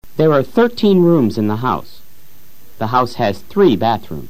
Repítelo luego simultáneamente con el profesor.
1. Unas veces suena como la d de dedo o dime.
2. Otras veces suena como la z española en zoológico: